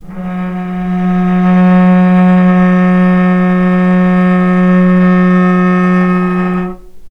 vc-F#3-mf.AIF